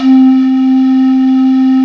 PAN FLU  C4.wav